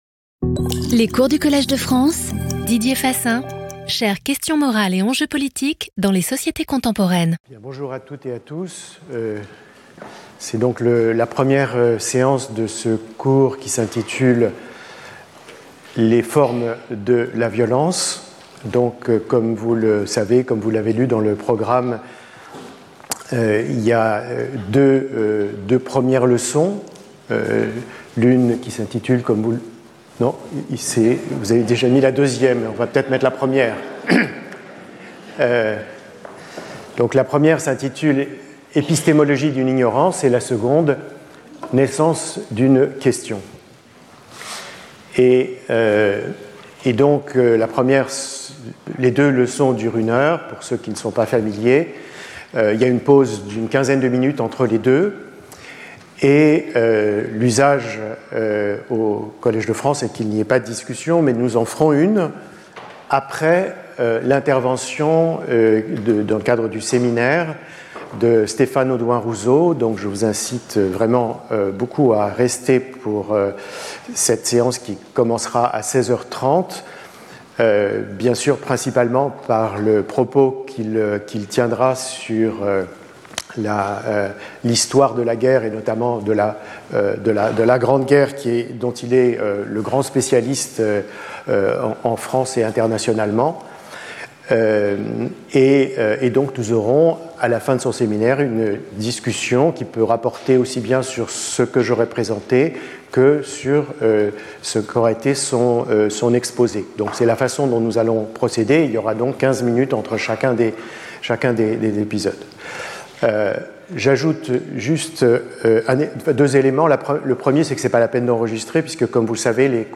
Lecture audio
Intervenant(s) Didier Fassin Professeur du Collège de France